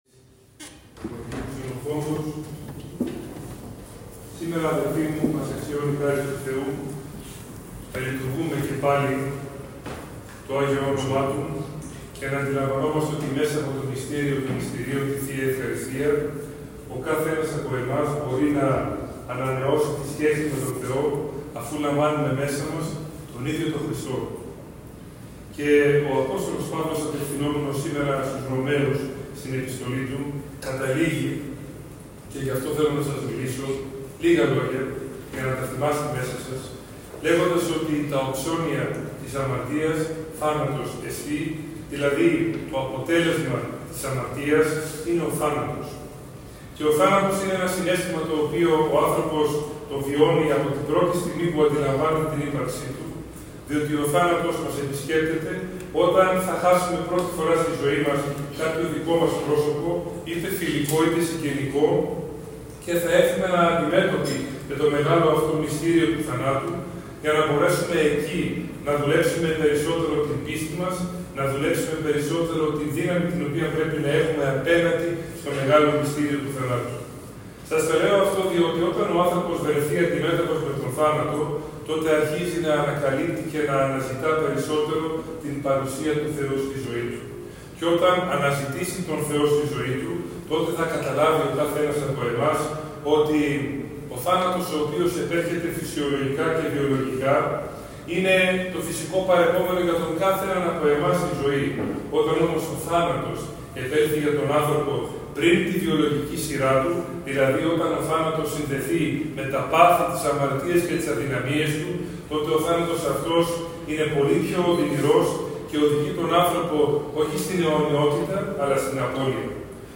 Ὁμιλία Σεβασμιωτάτου Μητροπολίτου Νέας Ἰωνίας, Φιλαδελφείας, Ἡρακλείου καὶ Χαλκδόνος κ.κ. Γαβριήλ στὸ Μετόχι τῆς Ἱ.Μ. Ξενοφῶντος Ἁγίου Ὄρους τὴν Κυριακὴ 6 Ἰουλίου 2025.
Ομιλία-Μητροπολίτου-Γαβριήλ.mp3